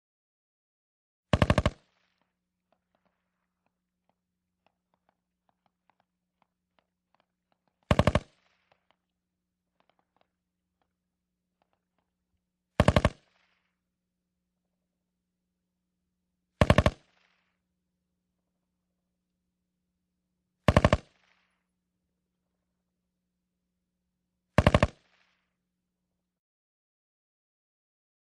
BritMachGunFire PE706902
WEAPONS - MACHINE GUNS HEAVY BRITISH GIMPY: EXT: Multiple bursts of five & four rounds, quick echos.